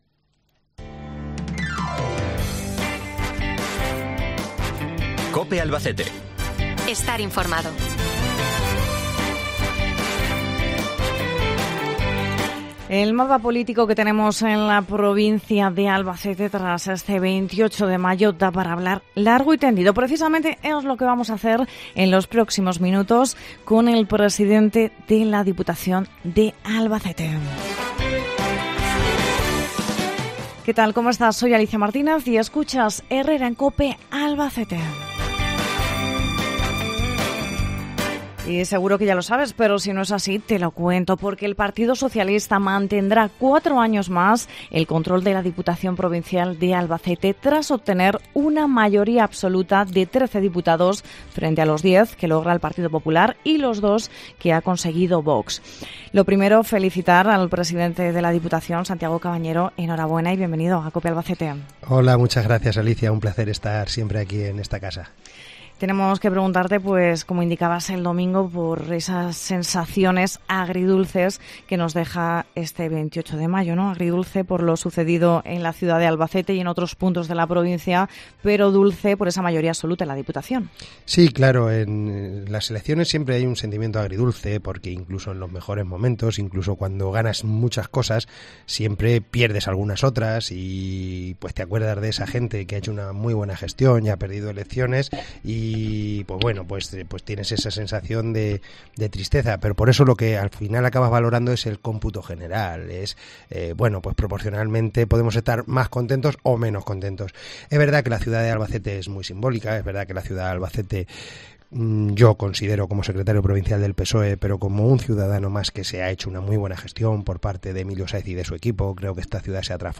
El presidente de la Diputación, Santiago cabañero, nos acompaña en Herrera en Cope Albacete para analizar los resultados de esta jornada electoral.